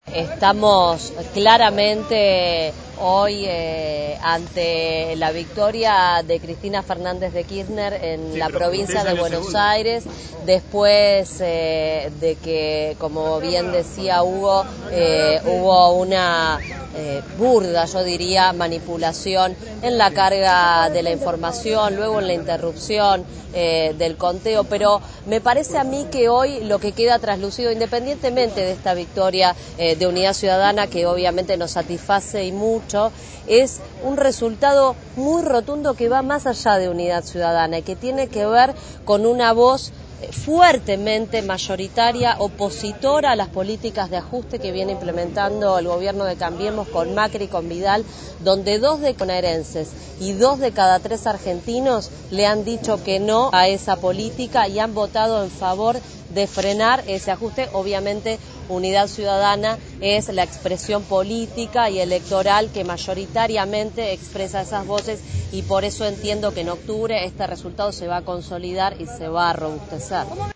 Después del acto, representantes de este espacio político dieron declaraciones a los medios de comunicación, y remarcaron con énfasis la manipulación de los datos en las PASO por parte del gobierno nacional.